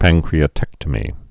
(păngkrē-ə-tĕktə-mē, păn-)